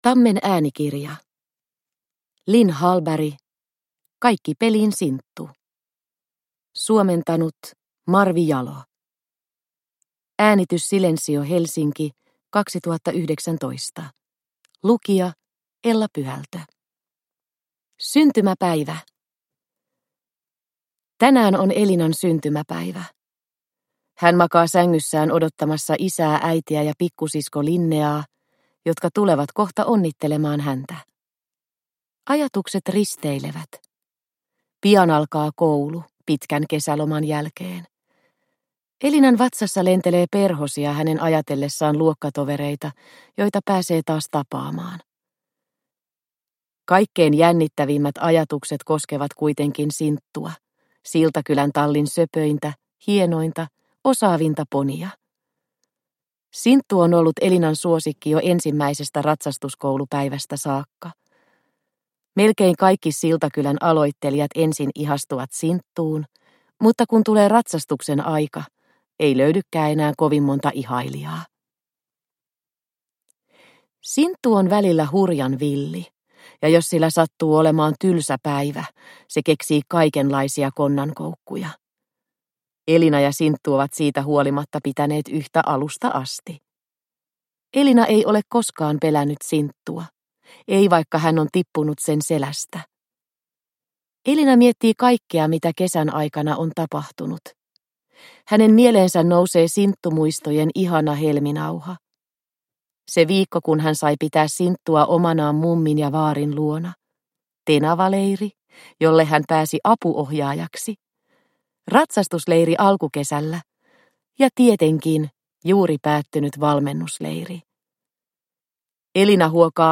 Kaikki peliin, Sinttu! – Ljudbok – Laddas ner